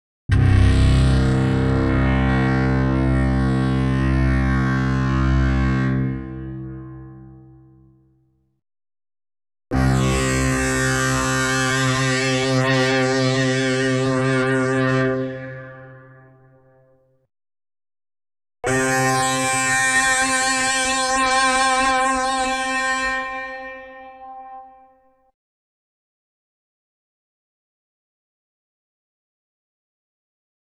04_Bratomat_C2_C3_C4.wav